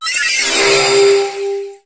Cri de Cosmovum dans Pokémon HOME.